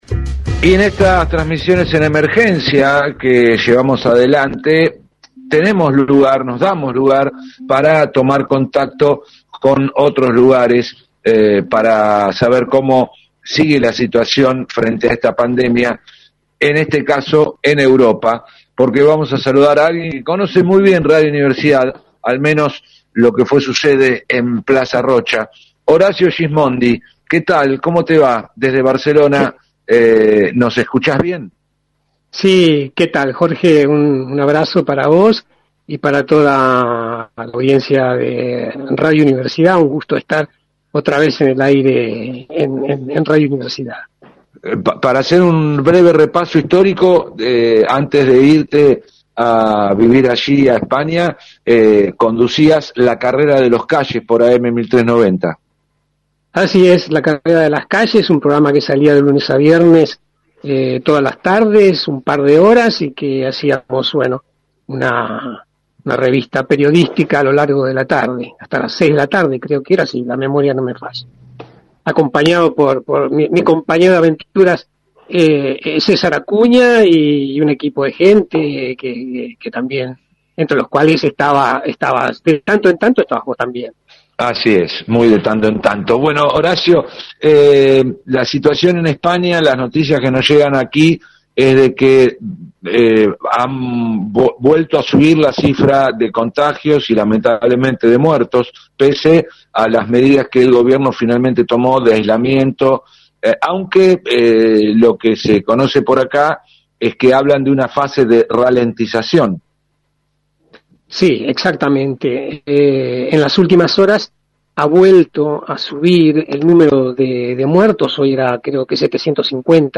La situación en España por la Pandemia: entrevista